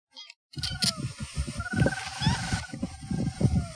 Ash-throated Crake (Mustelirallus albicollis)
Life Stage: Adult
Detailed location: Reserva Camba Trapo
Condition: Wild
Certainty: Recorded vocal